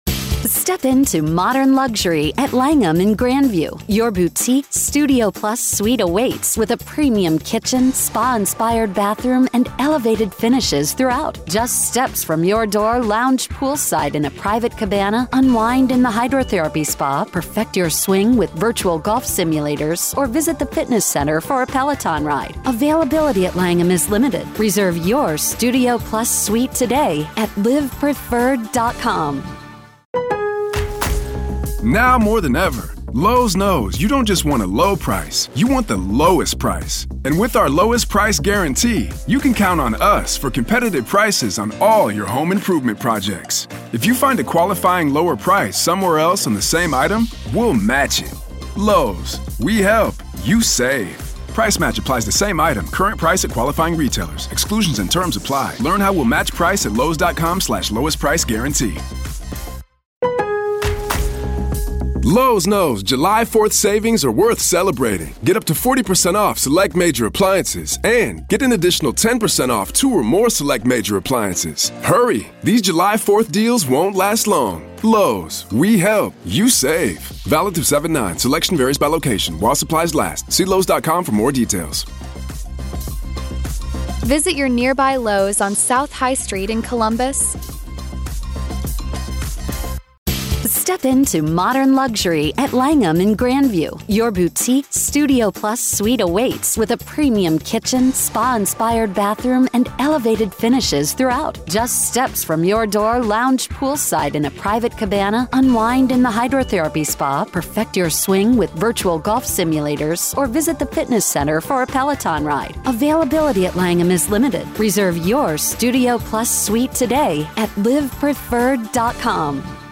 Is Kevin Franke a Victim or an Accomplice Of Ruby Franke, FBI Behavioral Expert Speaks